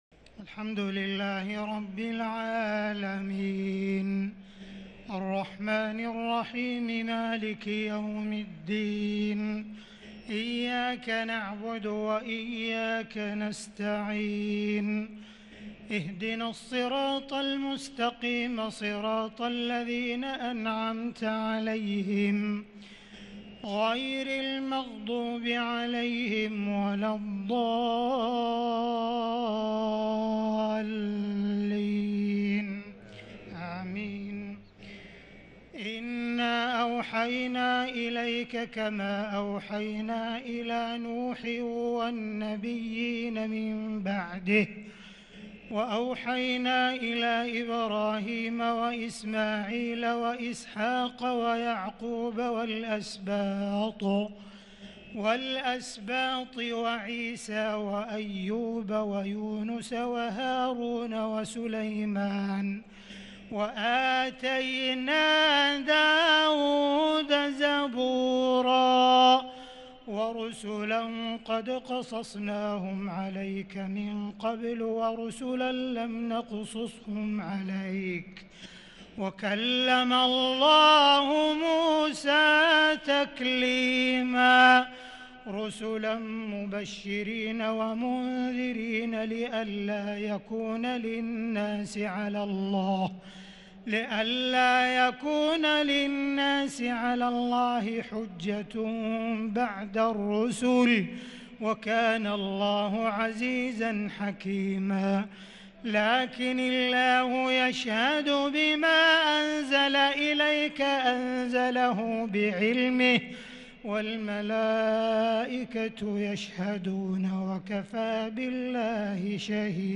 تراويح ليلة 7 رمضان 1443هـ من سورة النساء {163-176} Taraweeh 7st night Ramadan 1443H Surah An-Nisaa > تراويح الحرم المكي عام 1443 🕋 > التراويح - تلاوات الحرمين